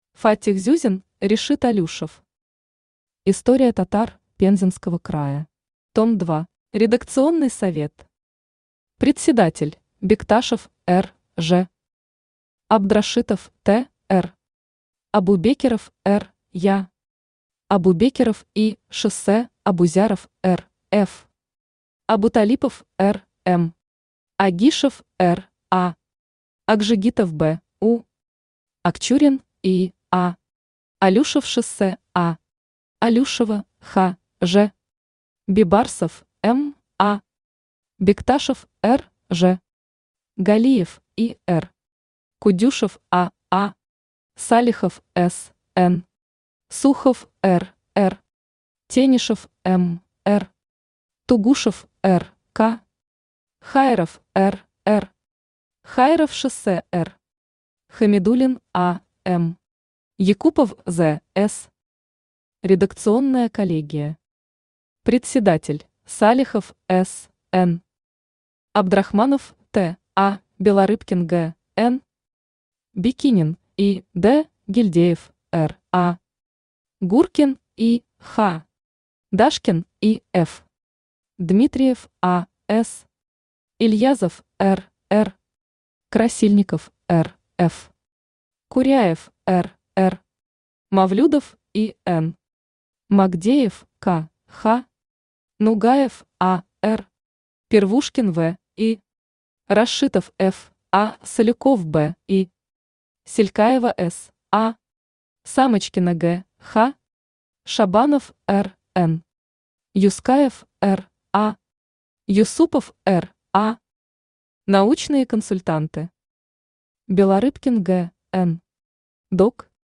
Том 2 Автор Фаттих Мухомятович Зюзин Читает аудиокнигу Авточтец ЛитРес.